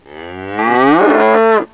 COW.mp3